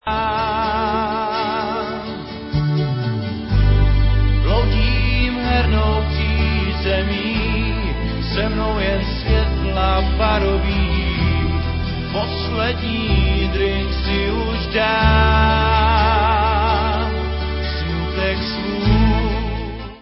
skvělými pomalými hity